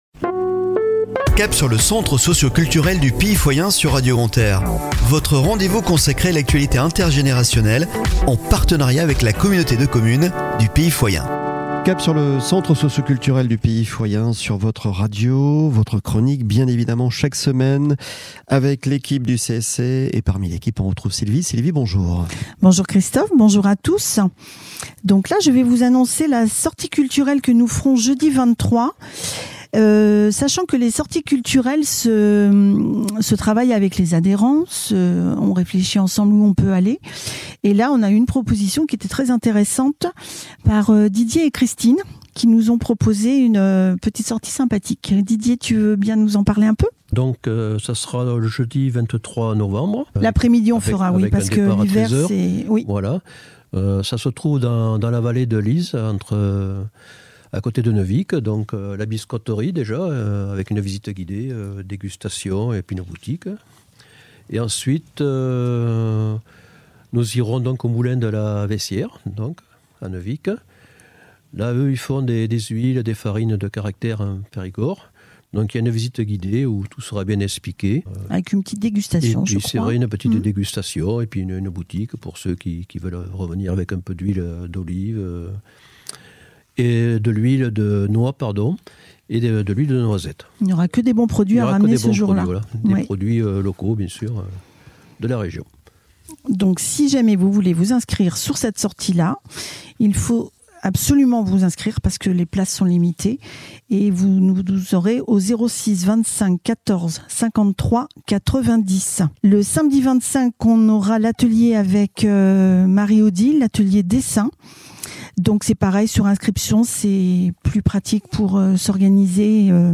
Chronique de la semaine du 13 au 19 Novembre 2023 !